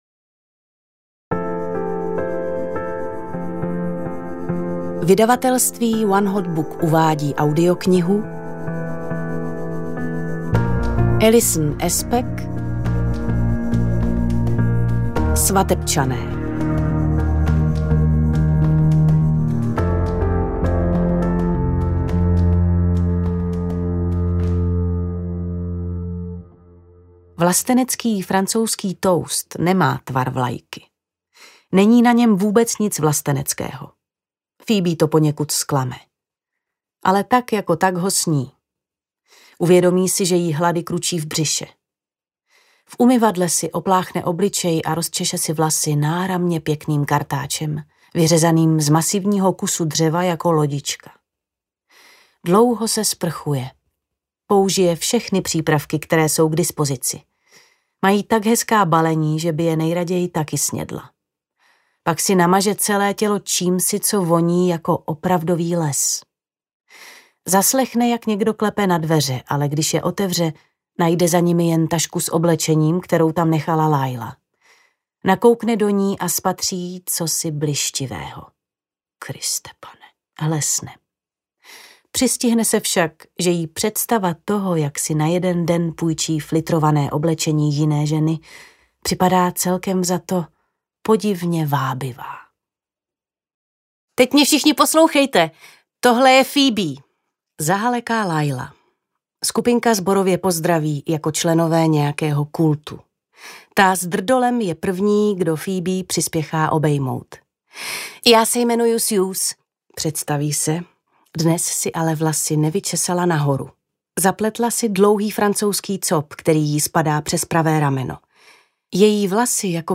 Svatebčané audiokniha
Ukázka z knihy
• InterpretMagdaléna Borová